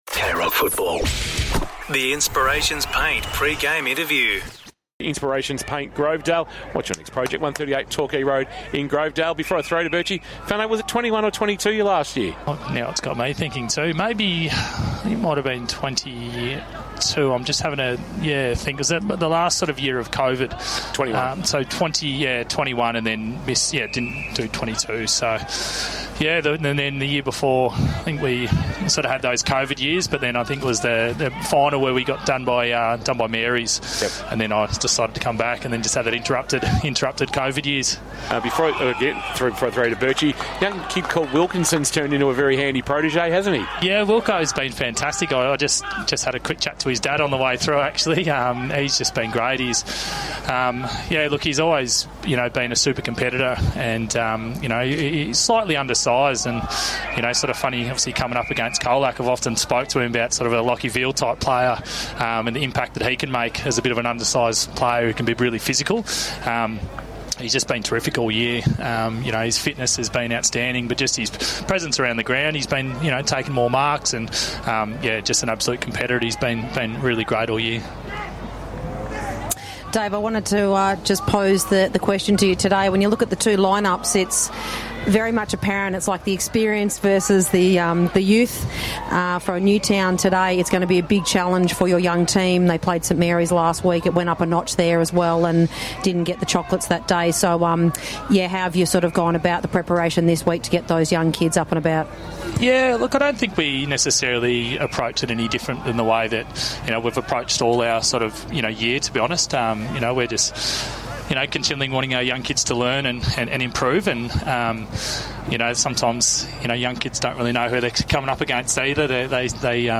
2025 - GFNL - Qualifying Final - Newtown & Chilwell vs. Colac - Pre-match interview